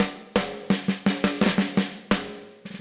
Index of /musicradar/80s-heat-samples/85bpm
AM_MiliSnareB_85-03.wav